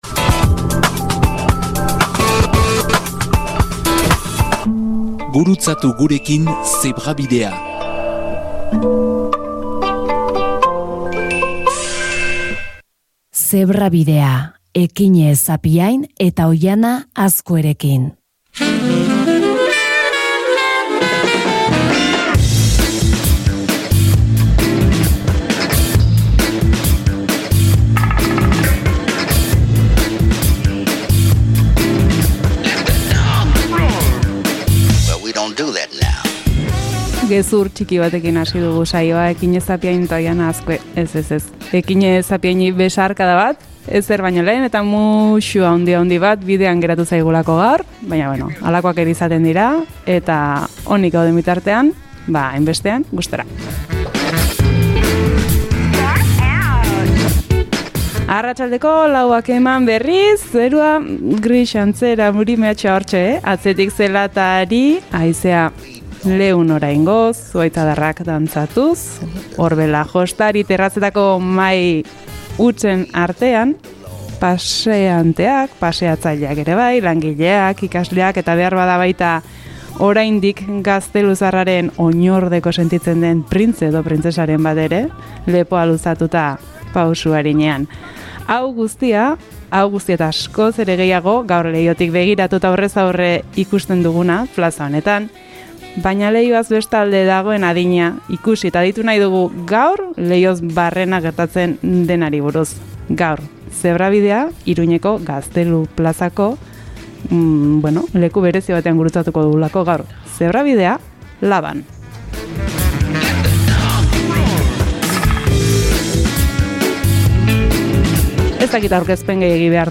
Jakin badakigu batzuetan Zebrabidea lekuz mugitzen dela. Hara eta hona ibiltzen gara, eta ortzegun honetan, Iruñeko LABA izan dugu geltoki.